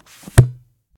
bookclose.ogg